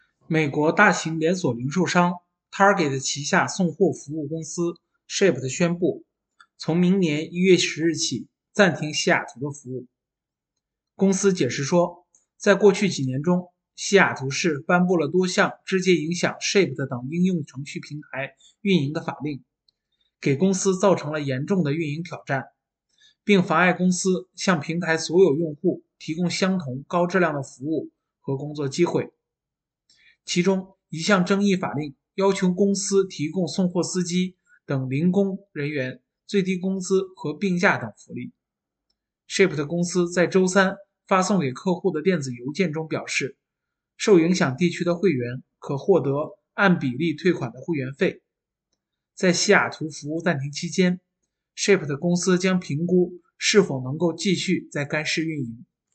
新聞廣播